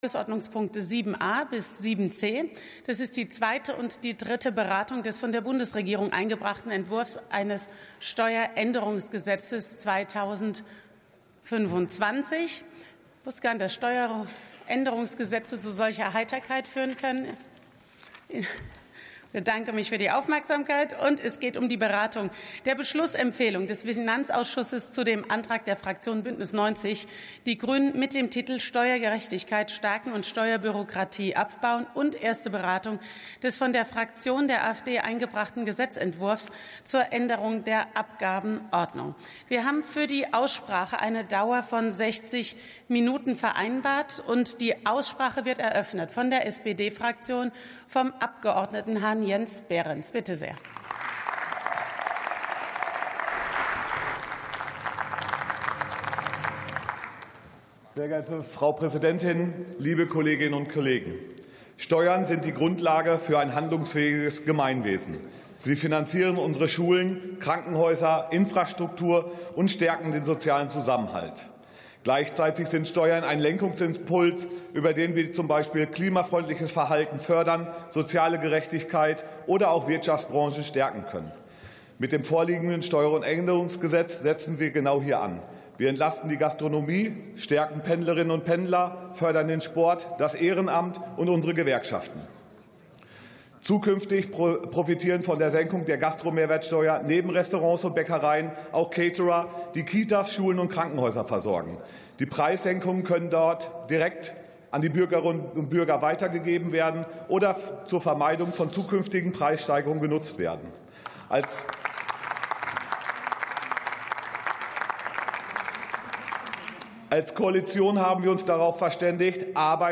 47. Sitzung vom 04.12.2025. TOP 7: Steueränderungsgesetz 2025 ~ Plenarsitzungen - Audio Podcasts Podcast